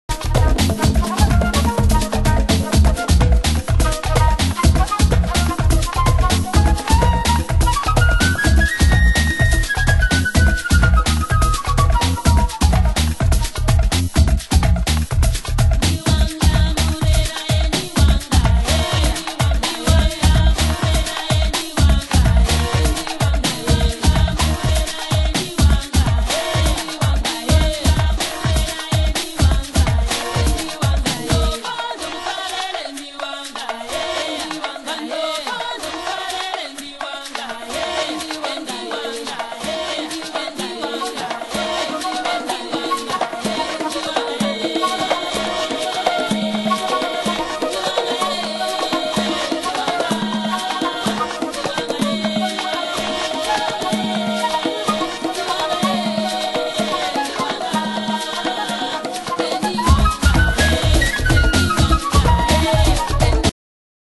HOUSE MUSIC
タフなアフロ・ビート＆野性味あふれるヴォーカル！